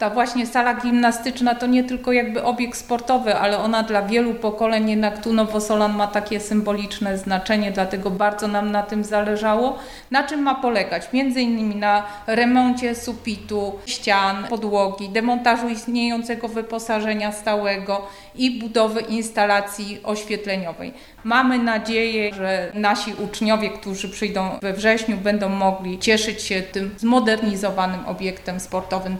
– Dlatego radni podjęli uchwałę o przeznaczeniu na ten cel środków z w budżetu powiatu – powiedział Sylwia Wojtasik, członek Zarządu Powiatu Nowosolskiego: